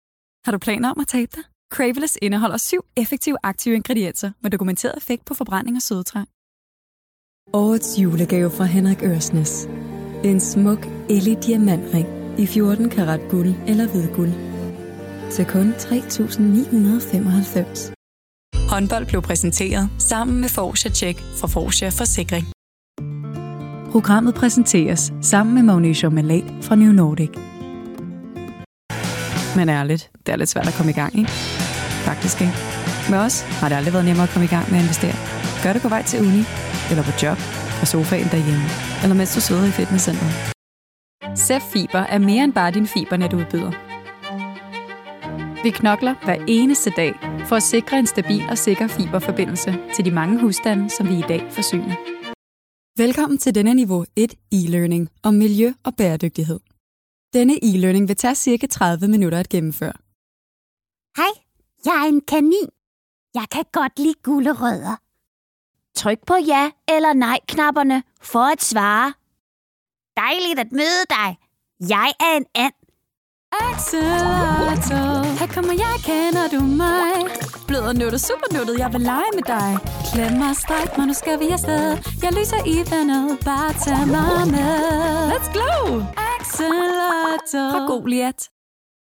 Danish, Scandinavian, Female, Home Studio, 20s-30s